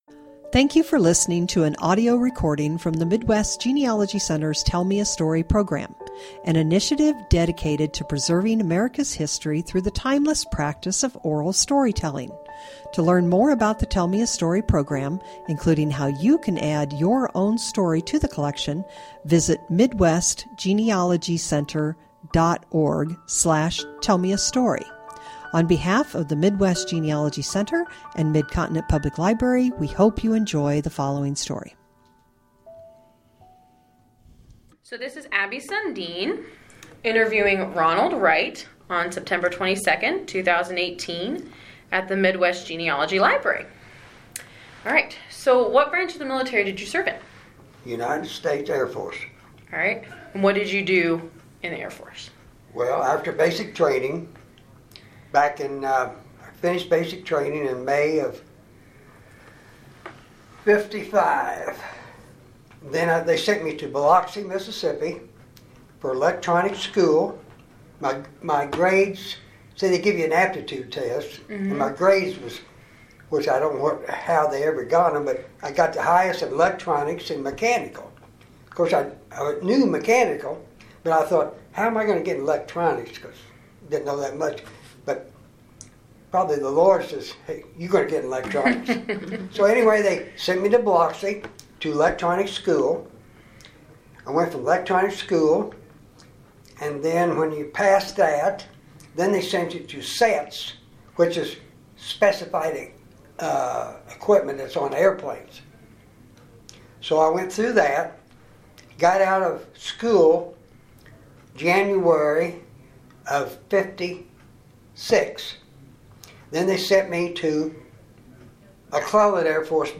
Oral Interview
Oral History